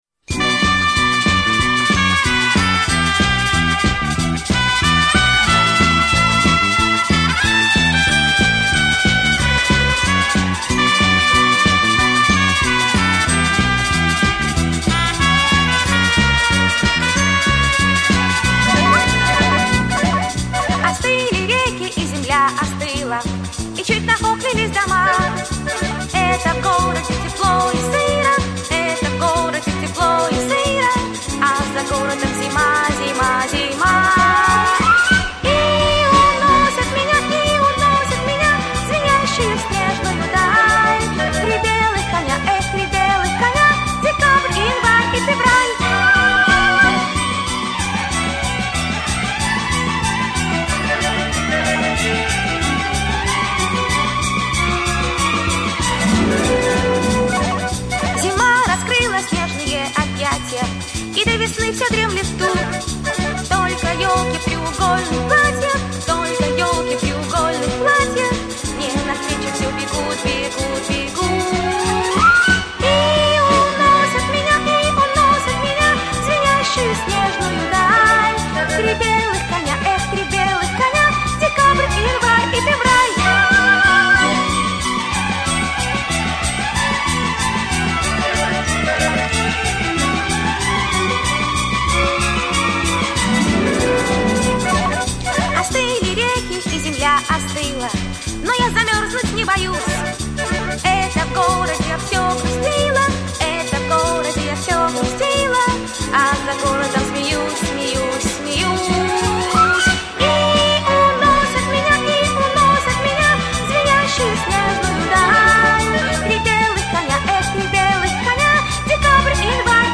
имитируя детский фальцет.